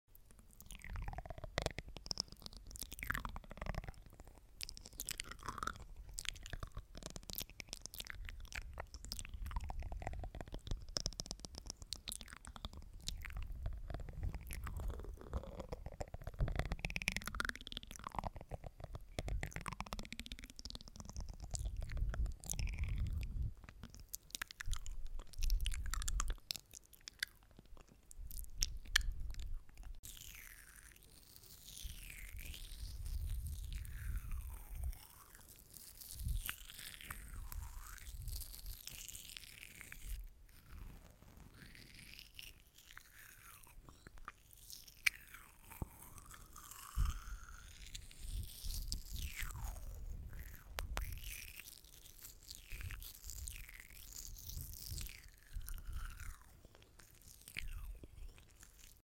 |ASMR| mermaid and spoly sound effects free download